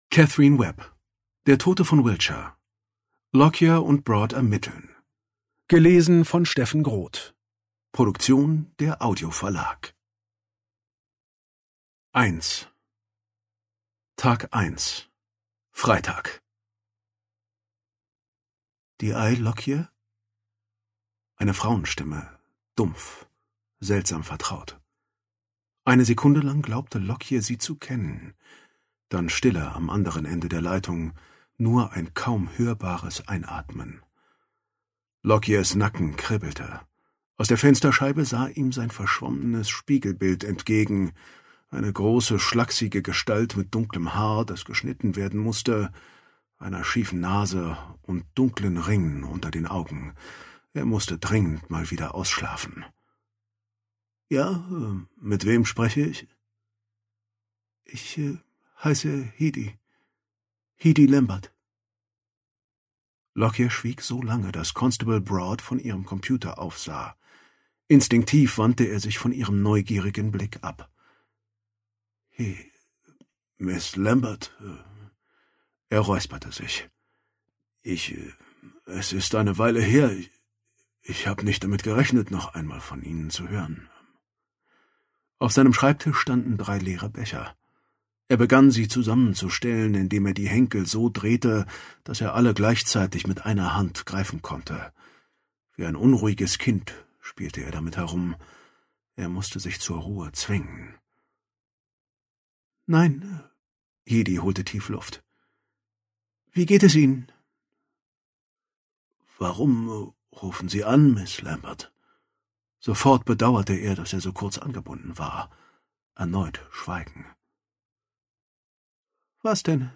Ungekürzte Lesung mit Steffen Groth (2 mp3-CDs)
Steffen Groth (Sprecher)